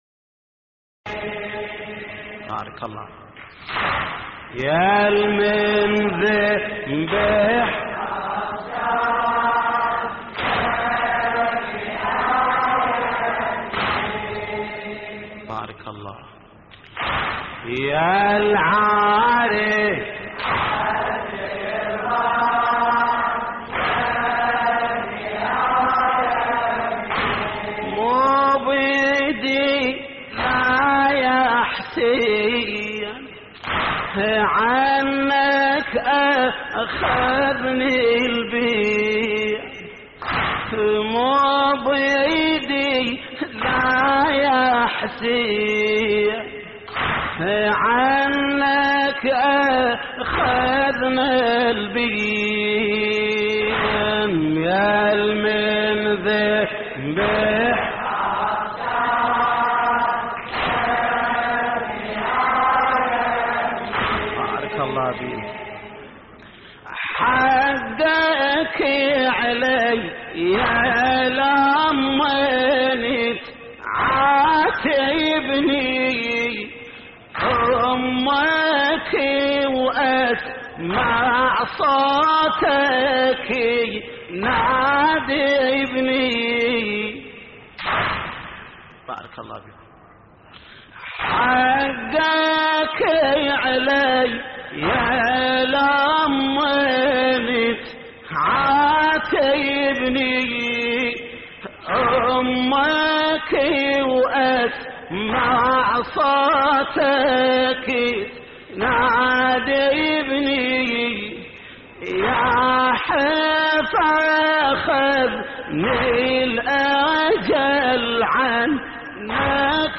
تحميل : يالمنذبح عطشان يبني آيبني يلعاري عالتربان يبني آيبني / الرادود جليل الكربلائي / اللطميات الحسينية / موقع يا حسين